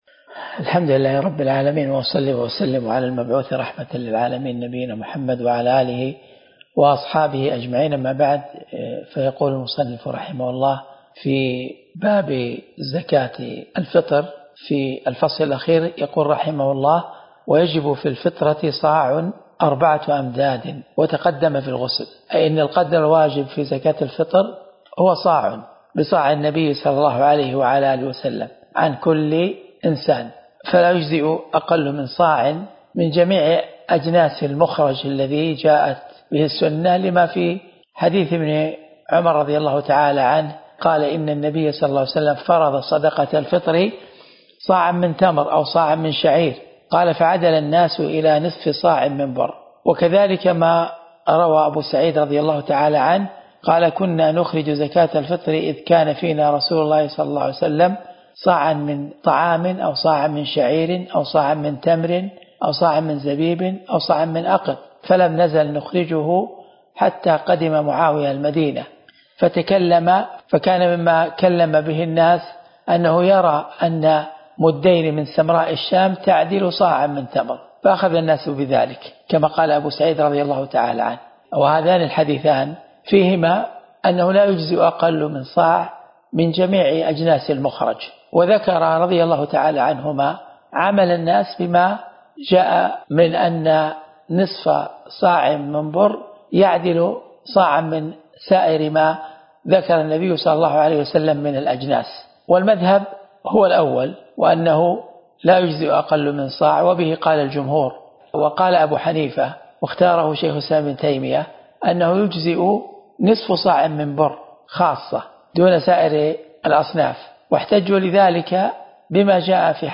الدرس (15) من شرح كتاب الزكاة من الروض المربع